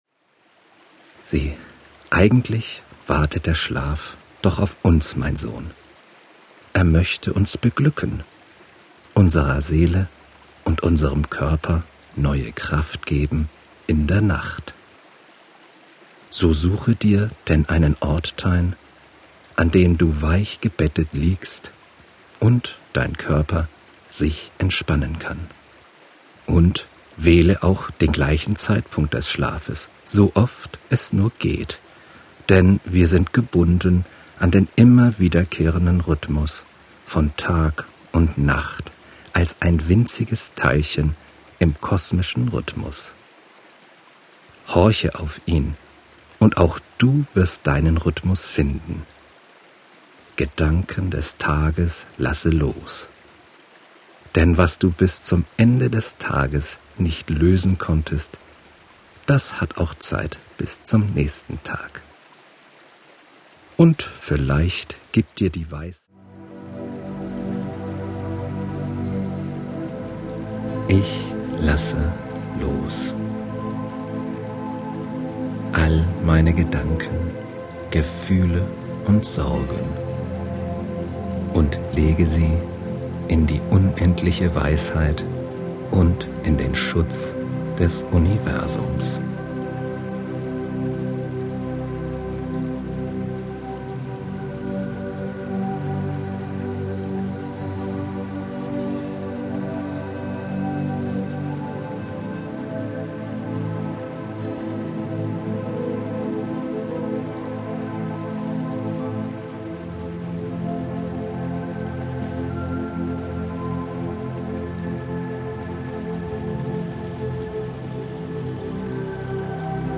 Einschlafhilfe nach ZEN mit meditativer Geschichte
Es folgt die Entspannungsübung mit Tiefenentspannungsformeln des ZEN als Überleitung in den Schlaf mit sanften und meditativen Klängen. Die Klänge und Naturgeräusche erklingen noch länger nach und führen Sie fernab von Ihrem Tagesgeschehen in Morpheus Arme.
syncsouls_mein_weg_in_Den_schlaf_zen_einschlafhilfe_auszug.mp3